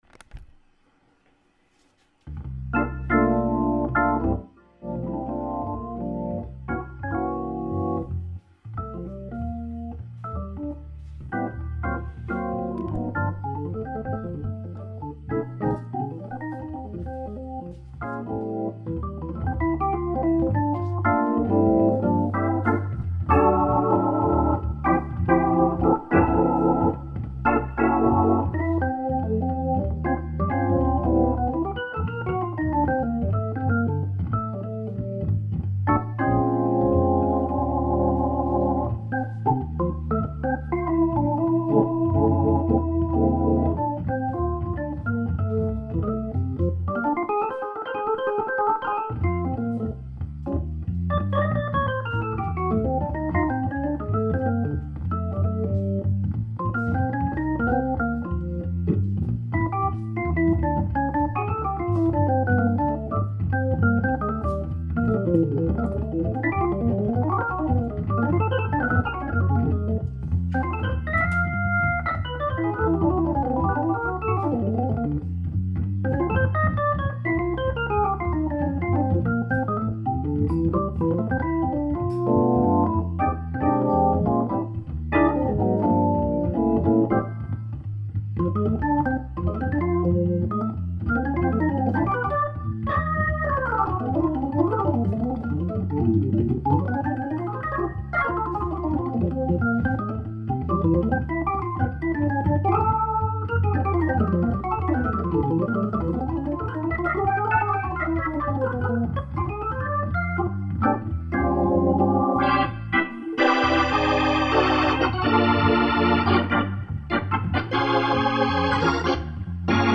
ça ressemble bien à une démo sur le XK effectivement ......